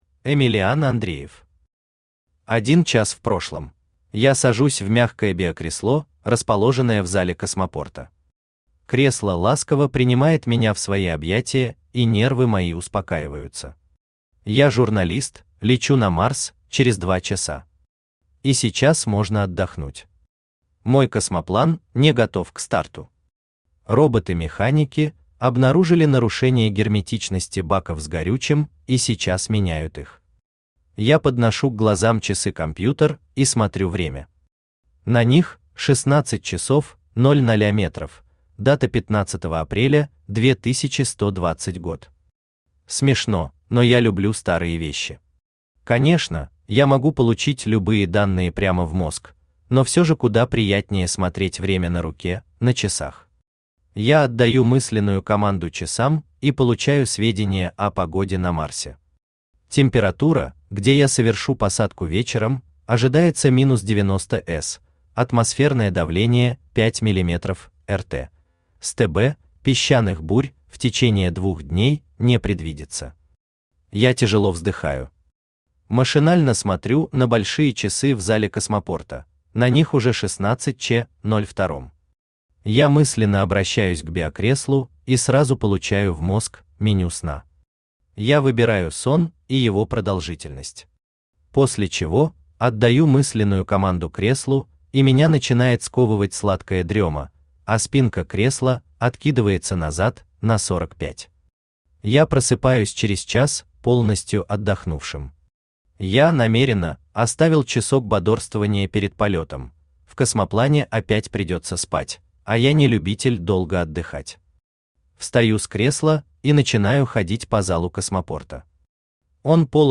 Аудиокнига Один час в прошлом | Библиотека аудиокниг
Aудиокнига Один час в прошлом Автор Эмилиан Андреев Читает аудиокнигу Авточтец ЛитРес.